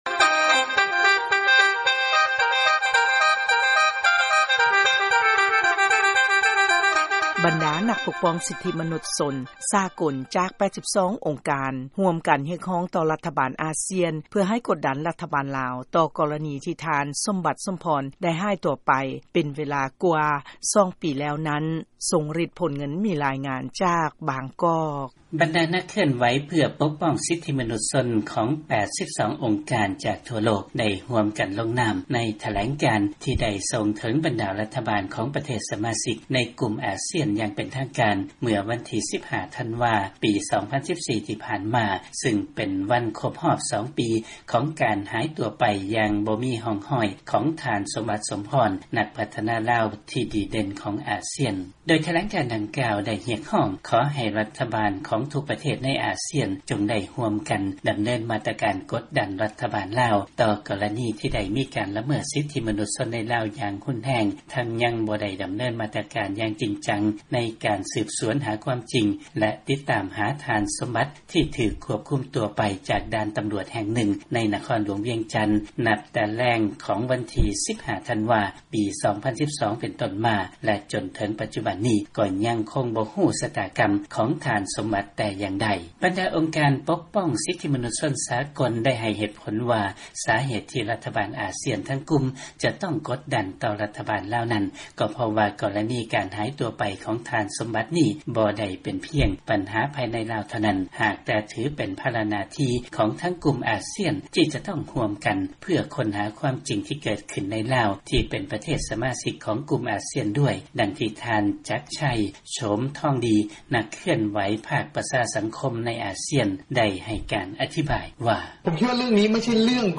ຟັງລາຍງານ ບັນດາກຸ່ມປົກປ້ອງສິດທິມະນຸດສາກົນ ຮຽກຮ້ອງຕໍ່ອາຊ່ຽນ ໃຫ້ກົດດັນລາວ ເລື້ອງທ່ານສົມບັດ.